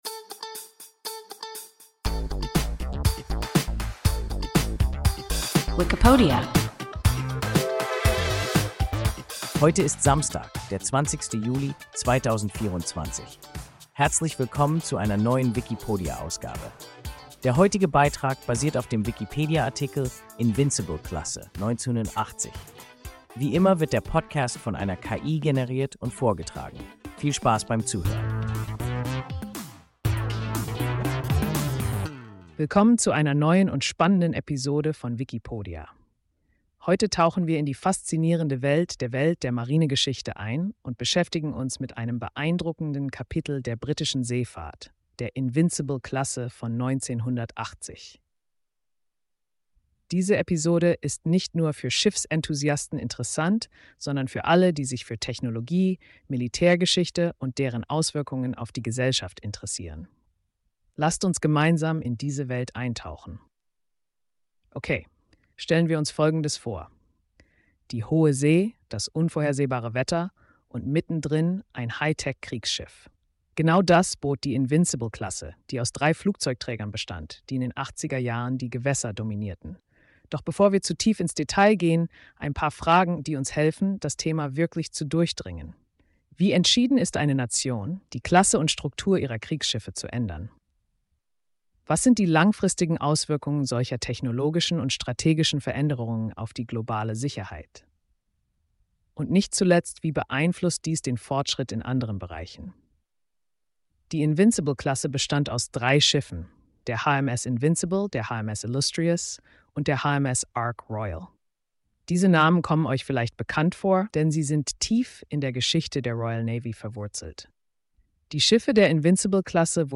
Invincible-Klasse (1980) – WIKIPODIA – ein KI Podcast